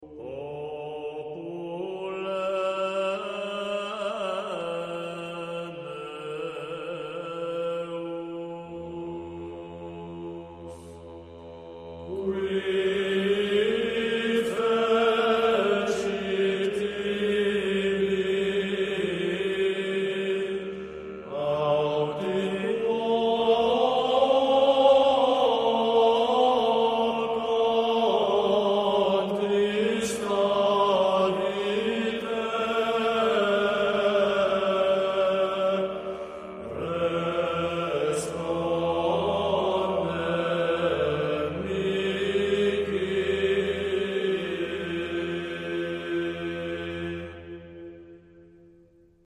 Esecuzione sulla scorta di codici del rito romano antico.
La seconda parte ribadisce gli stessi concetti sopra espressi ma suddivisi in nove improperia (cantati dai soli su modello salmodico) intercalati dal coro che ripete ogni volta i primi versi con cui aveva esordito nella prima parte: "Popule meus, quid feci tibi? Aut in quo contristavi te? Responde mihi!":